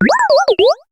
Cri de Croquine dans Pokémon HOME.